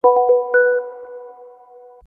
SmartPhoneStartup.wav